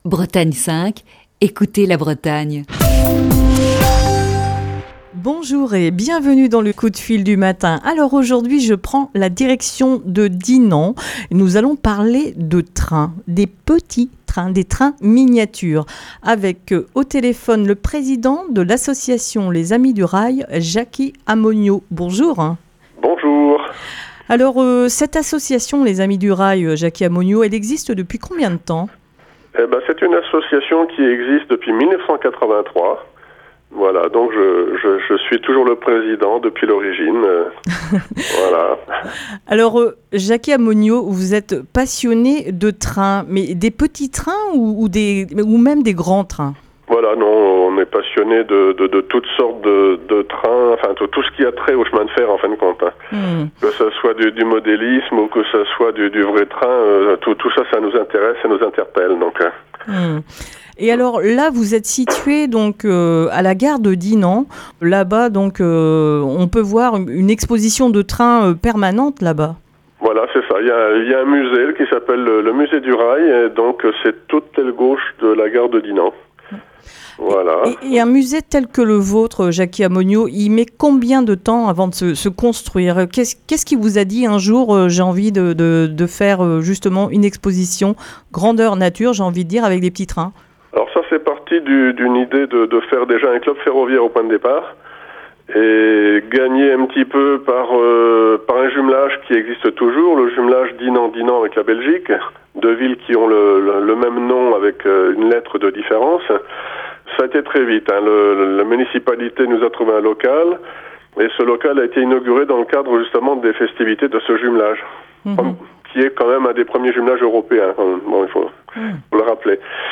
L'association, située dans la gare de Dinan, anime le musée du rail qui réunit une collection importante d'objets ferroviaires et de trains miniatures. (Émission diffusée le 12 mars 2020).